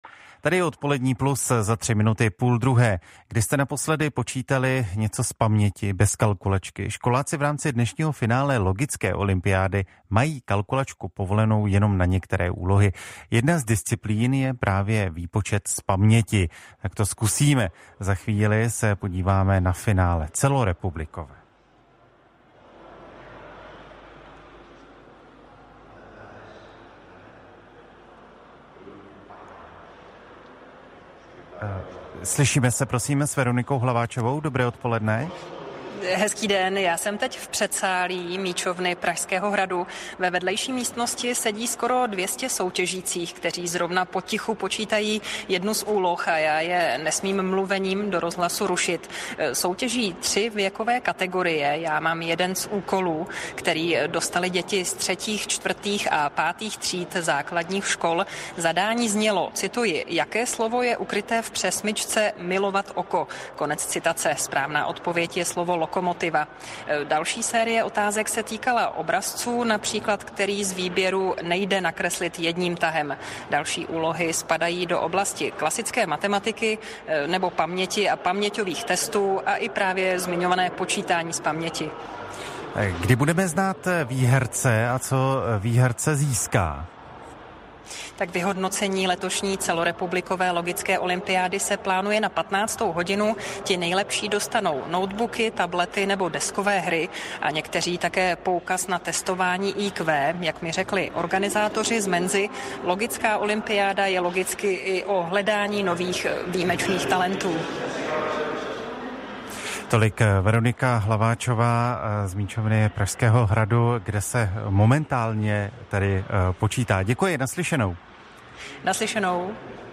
Reportáž ČRo - Přímý vstup do Míčovny